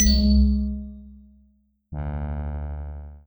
JourneyToNowhere_Unity/Assets/Bundles/Audio/SFX/UI_OpenMenu_Start.wav at 578388f144eab2bd31f997b7e86937be4bf648f0
UI_OpenMenu_Start.wav